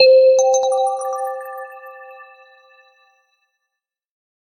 Здесь вы найдете как знакомые «хрустящие» эффекты корзины, так и более современные варианты.
Звук необратимого удаления данных